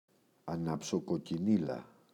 αναψοκοκκινίλα, η [anapsokoki’nila]